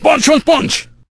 el_primo_atk_03.ogg